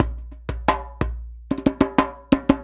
90 JEMBE2.wav